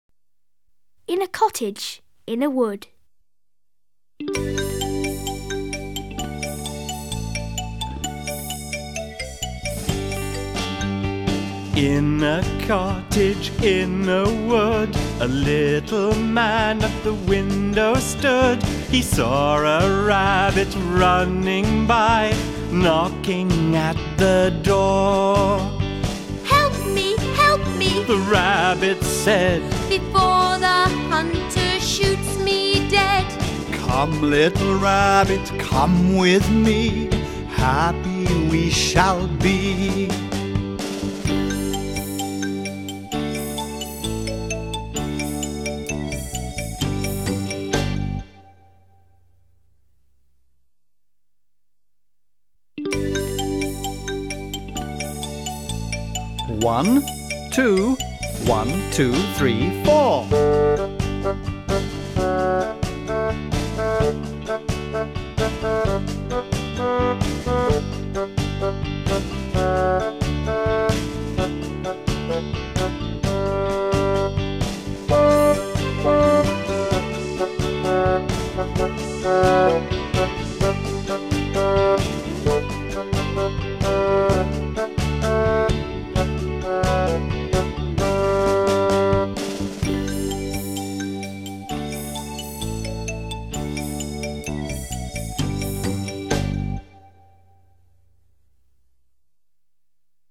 Фестиваль "День английского языка"
Песня "В домике, в лесу" - [1] (поют дети 2 класса).